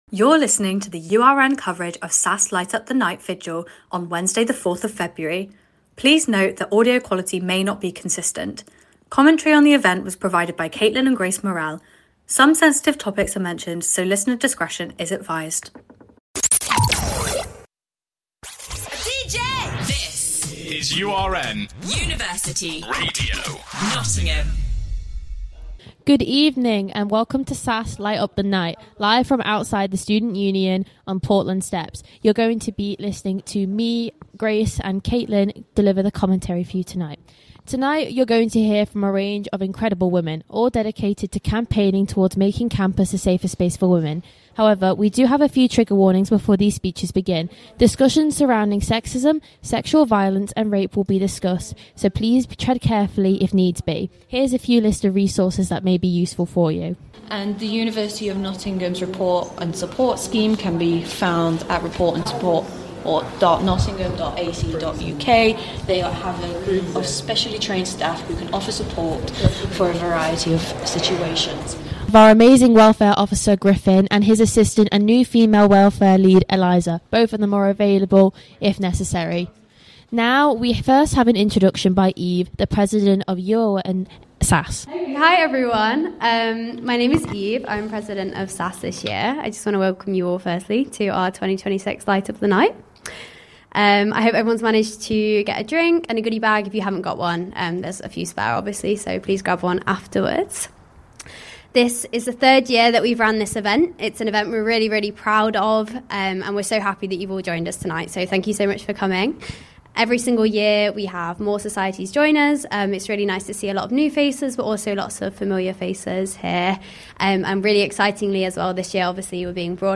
URN's Broadcast of SASS's (Students Against Sexual Assault and Sexism) Light Up the Night Vigil.